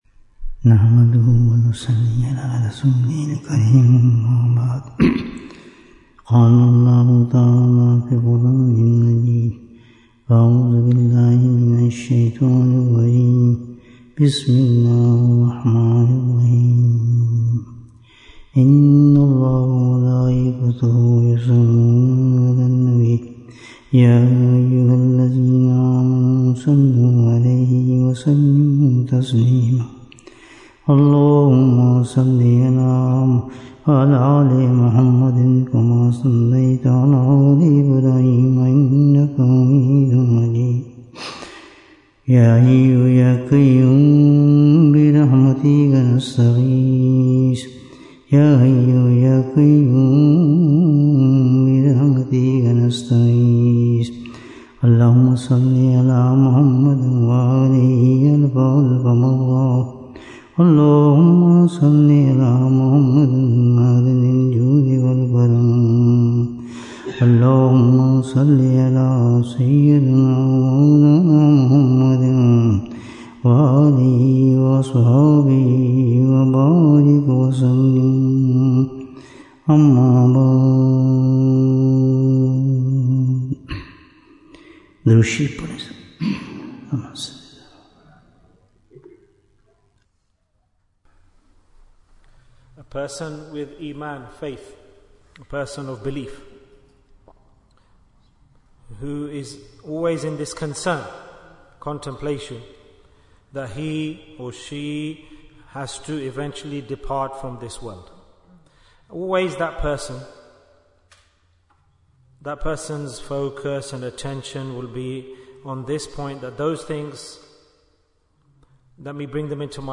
Bayan, 79 minutes 20th February, 2025 Click for Urdu Download Audio Comments What is the Biggest Obstacle in the Path of Allah?